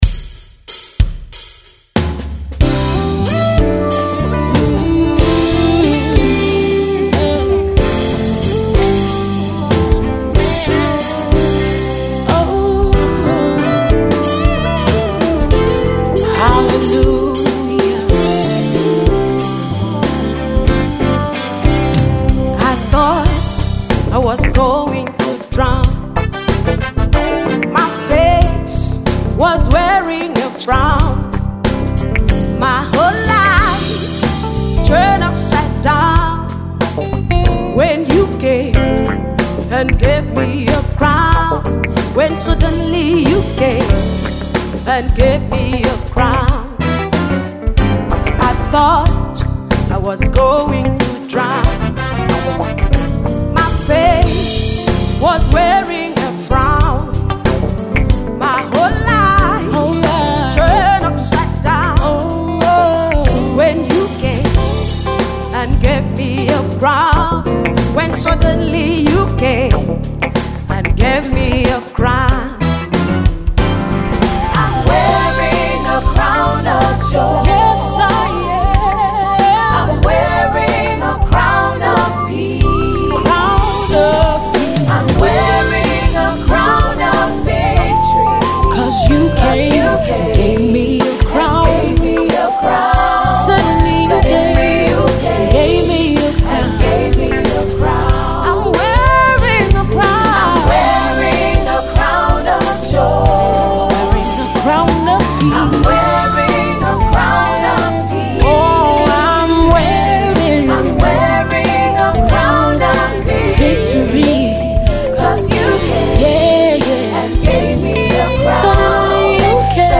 ” a contemporary gospel tune accompanied by a music video.